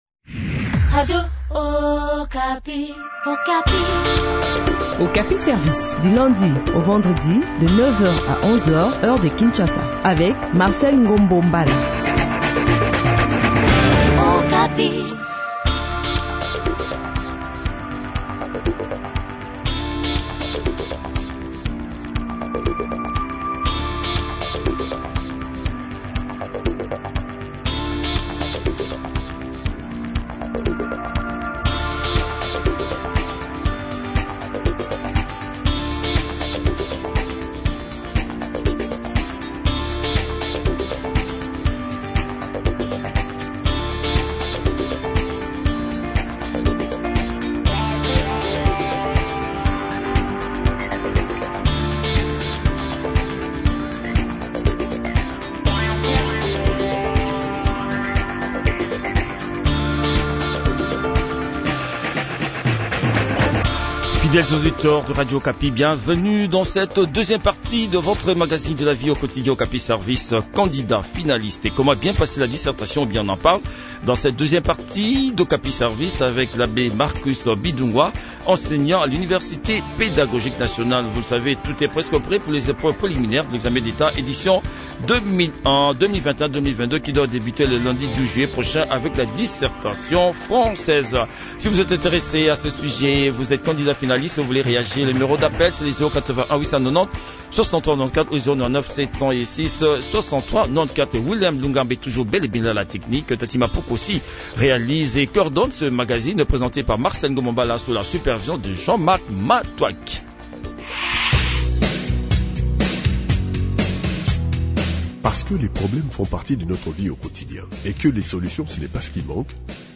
s’entretient sur ce sujet